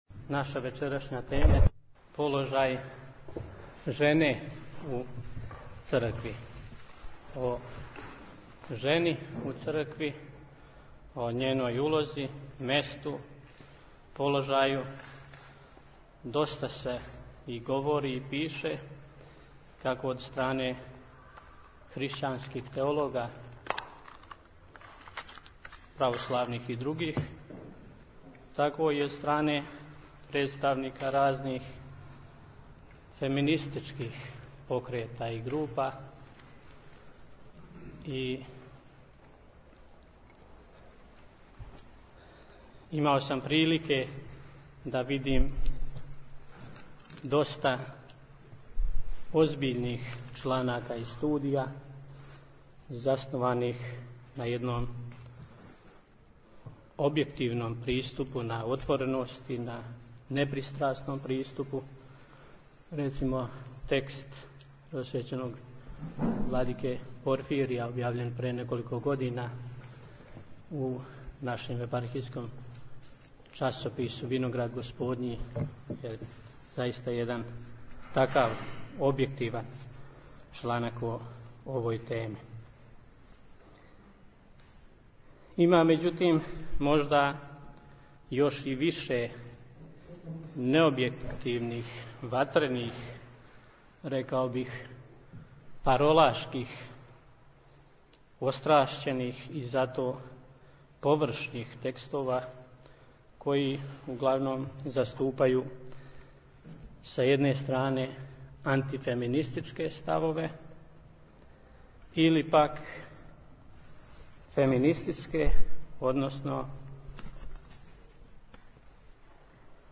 Звучни запис предавања